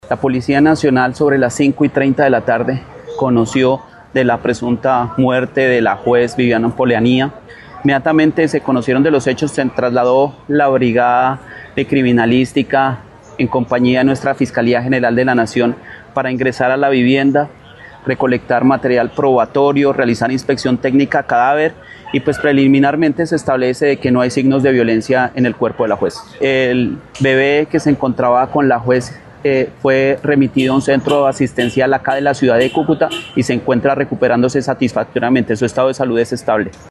El Coronel Libardo Fabio Ojeda Eraso Comandante de la Policía Metropolitana de Cúcuta, indicó que no se hallaron signos de violencia en el cuerpo de la jurista.